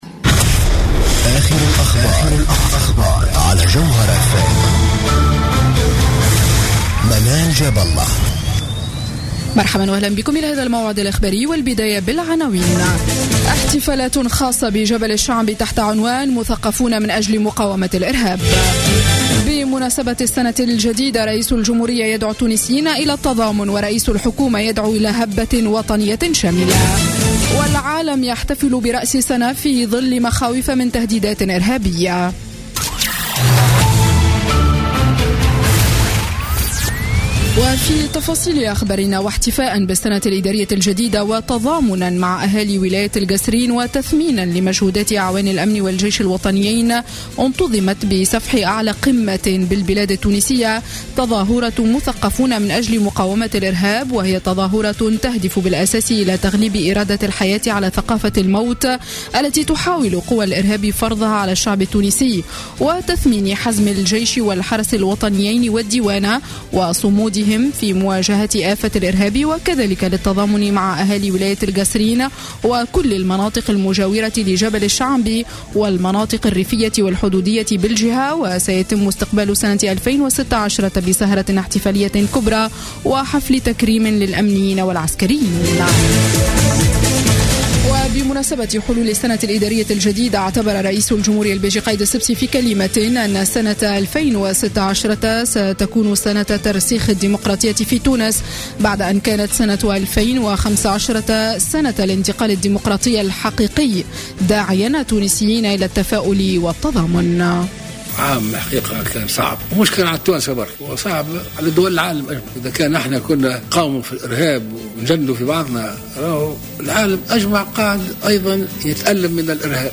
نشرة أخبار الحادية عشرة ليلا ليوم الخميس 31 ديسمبر 2015